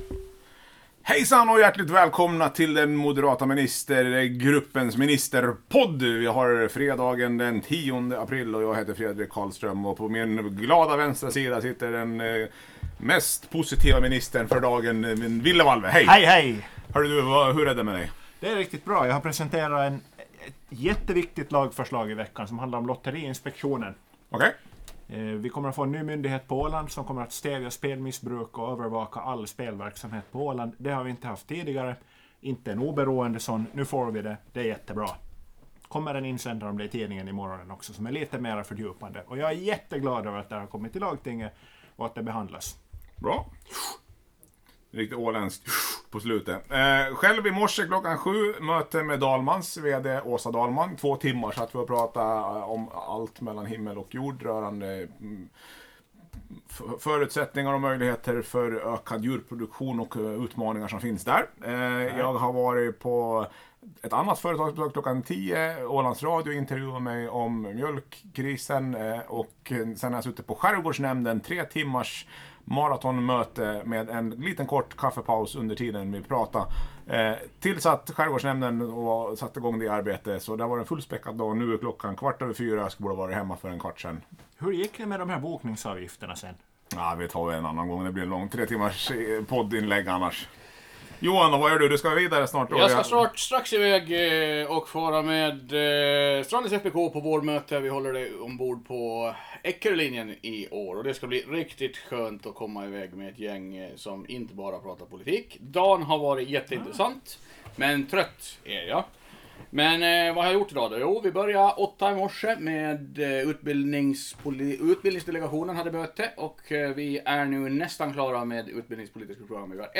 Tre ministrar pratar kort om dagen, vecka och kommande dagar. Ansvarig utgivare tar inte ansvar för kvalité i vare sig innehåll eller ljudteknik. Ont om tid och stressade ministrar är ingen bra kombination.